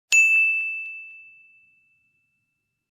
ding.opus